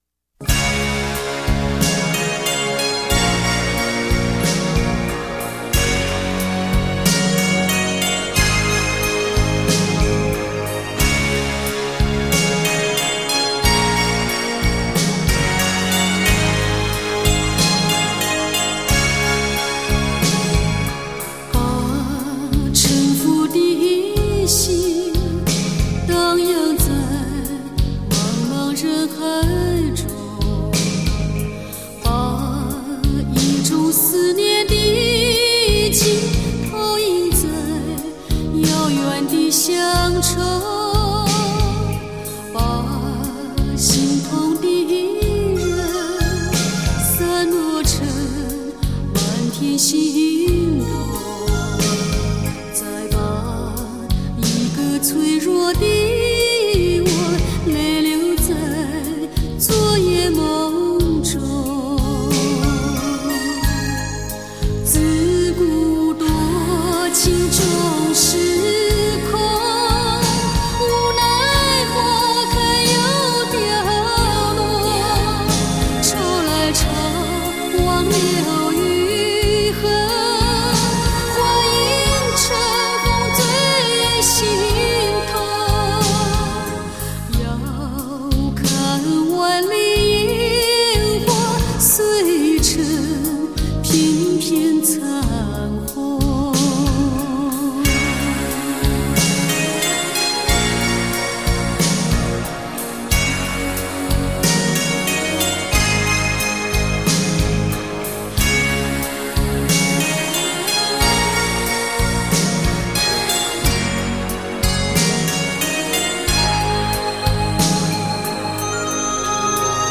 柔情名曲 珍爱一生
新古典的浪漫 后现代的抒情